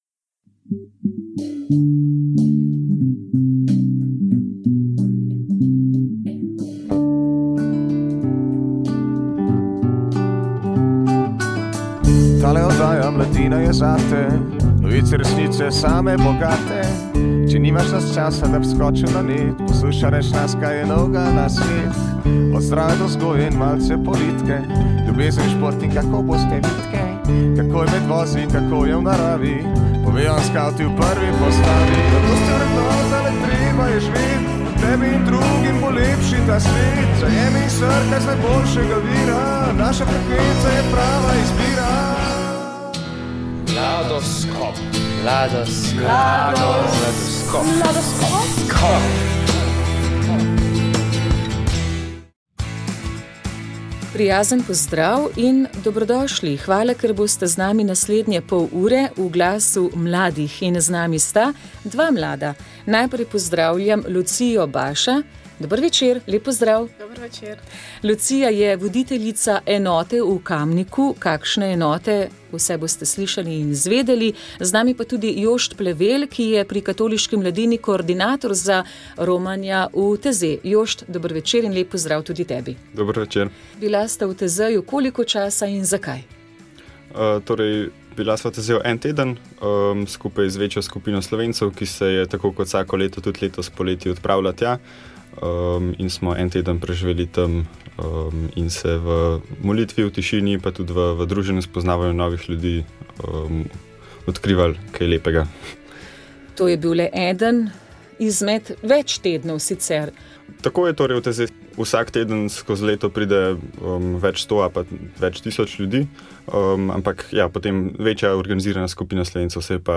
V steno najvišjega ne-osemtisočaka so nas popeljala Doživetja z enim od obeh glavnih akterjev, ki sta 4. avgusta letos priplezala na 15. najvišjo goro na svetu po prvenstveni smeri. O plezanju po zahodnem grebenu Gašerbruma III. je spregovoril naš gost, vrhunski alpinist Aleš Česen.